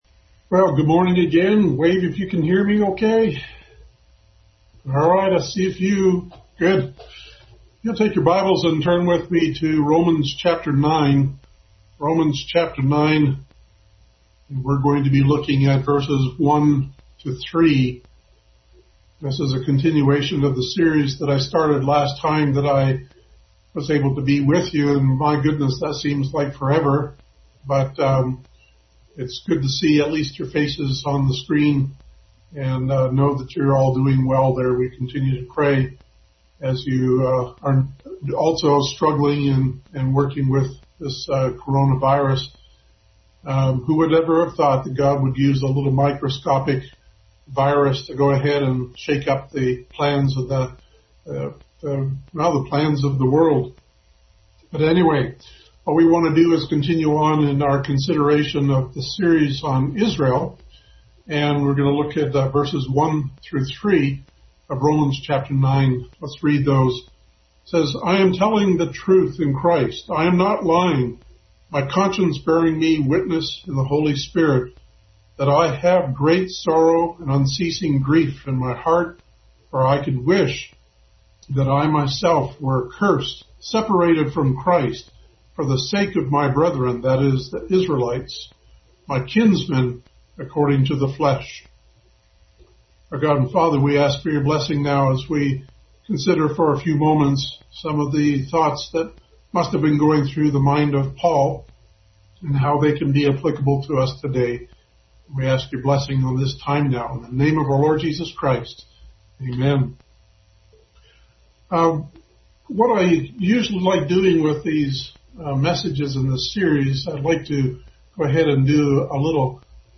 Bible Text: Romans 9:1-3, Philippians 3:2-7, Acts 7:54-60; 8:1-3; 22:3-5; 9:1-9 | Family Bible Hour Message.